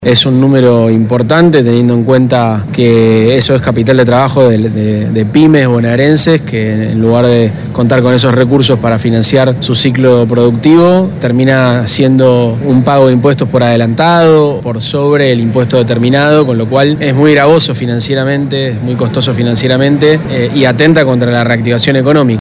El titular de ARBA, Cristian Girard, confirmó que se diseñaron mecanismos de devolución más ágiles, transparentes y rápidos, al tiempo que dijo que ese dinero se usa para reactivar la producción:
Girard_devolucionweb-1.mp3